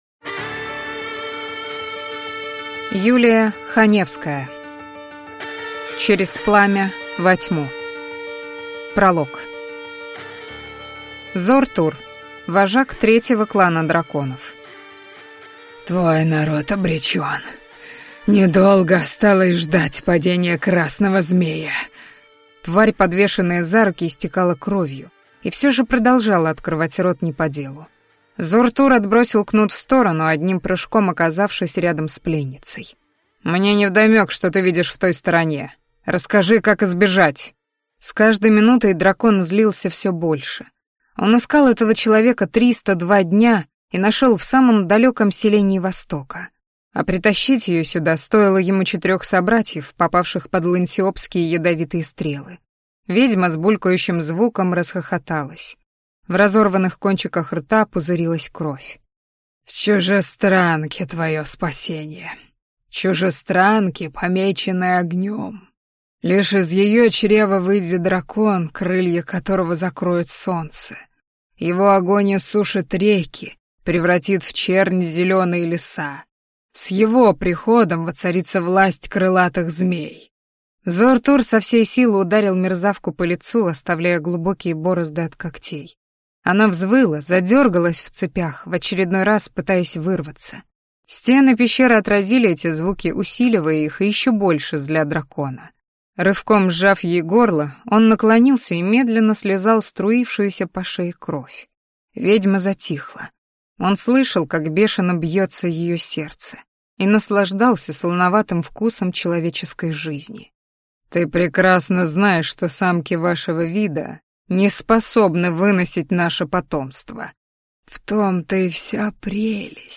Аудиокнига Через пламя во тьму | Библиотека аудиокниг